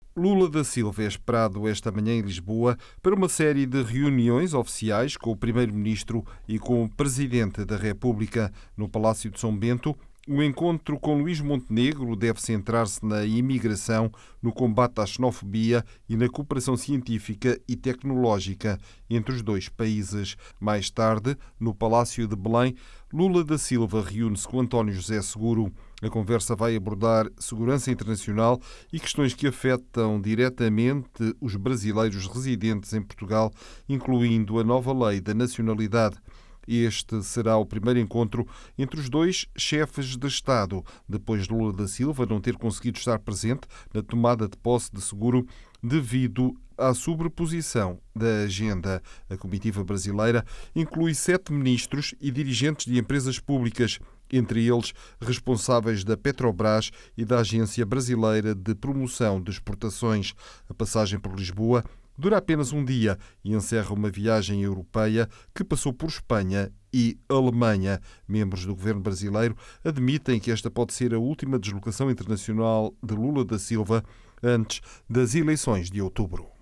como conta o jornalista